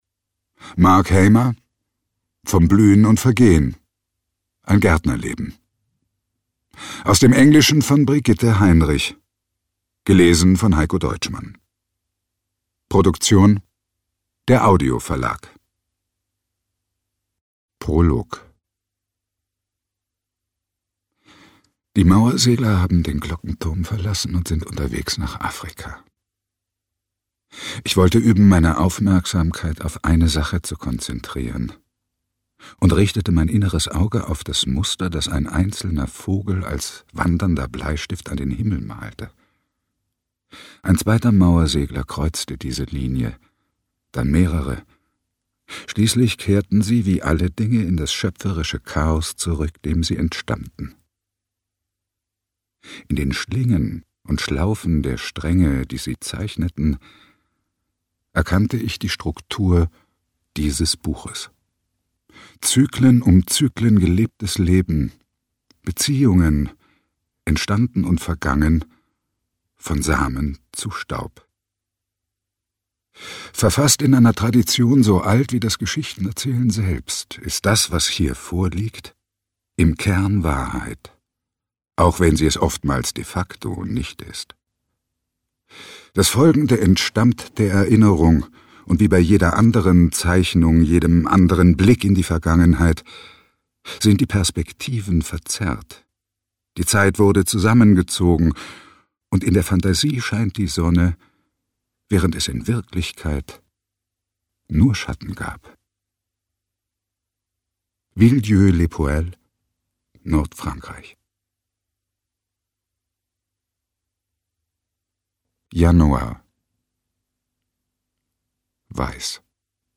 Hörbuch: Vom Blühen und Vergehen.